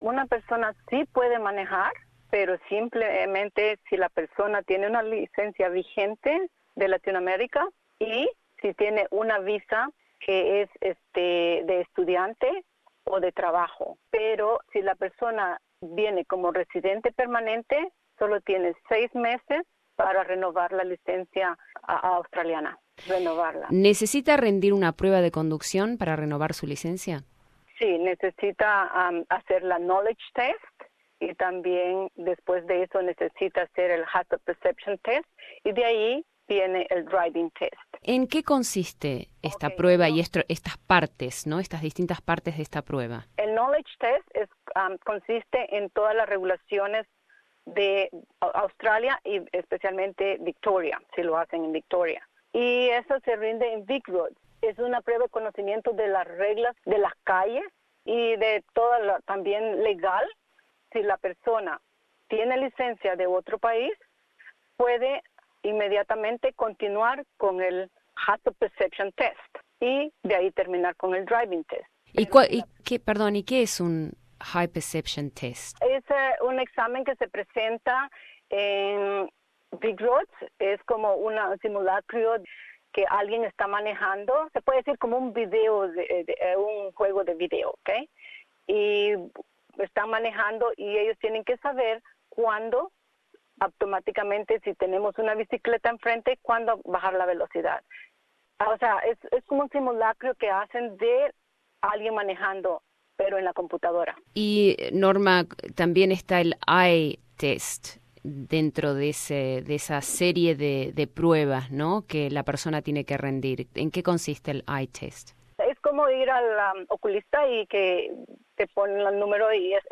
En entrevista con Hora 13